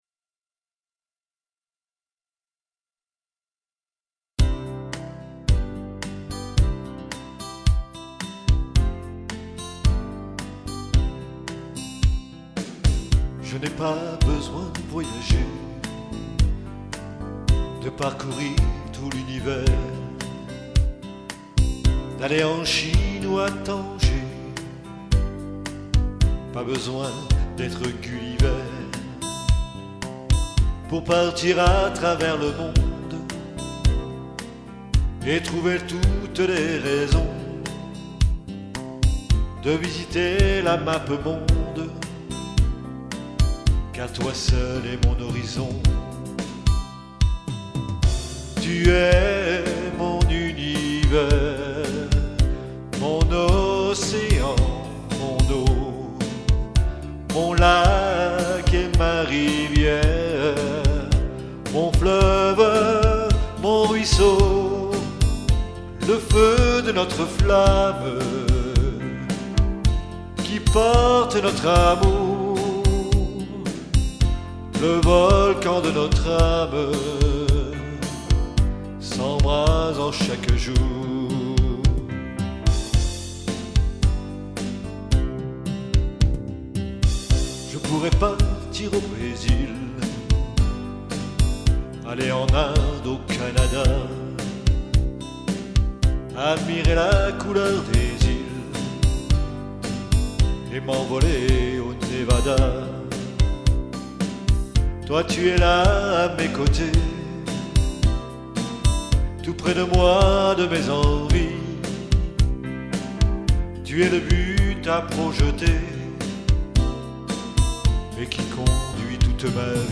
Personnellement j'aurais enregistré la musique dans une tonalité un peu plus haute. Je trouve que ta voix est plus belle quand tu chantes un peu plus haut.